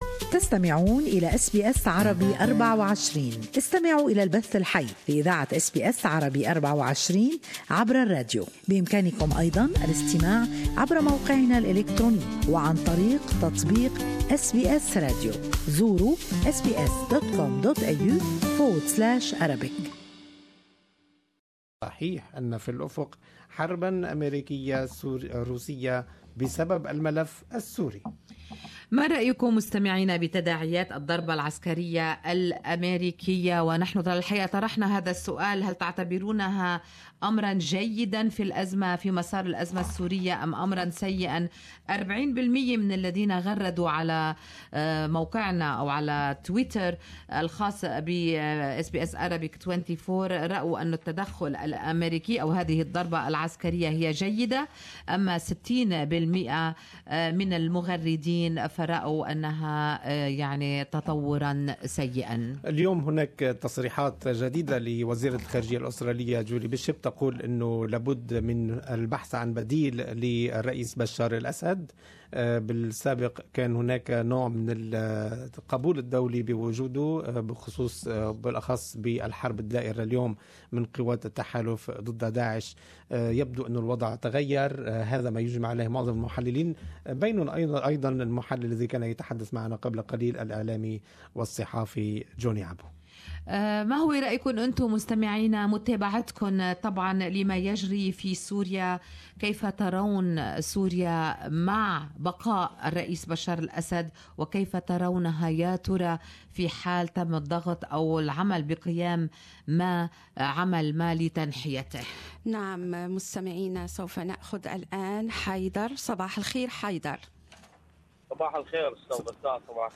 Good Morning Australia listeners share their opinion on this topic.